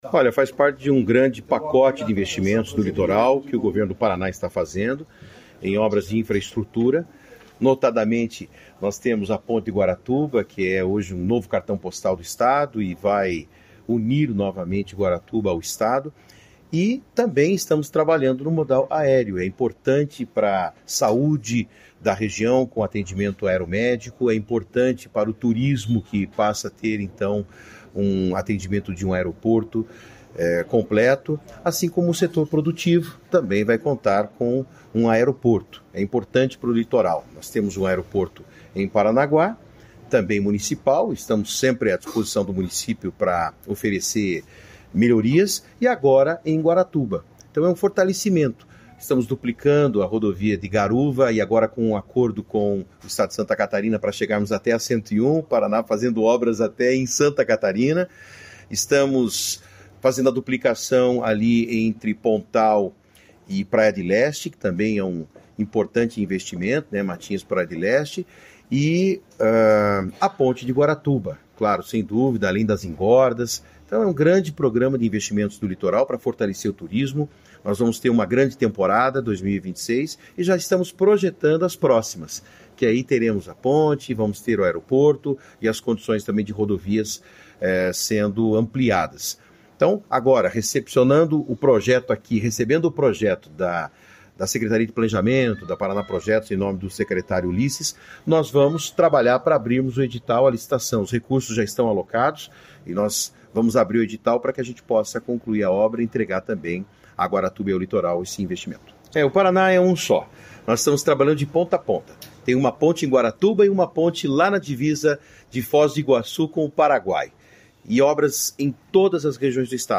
Sonora do secretário de Infraestrutura e Logística, Sandro Alex, sobre os novos investimentos em Guaratuba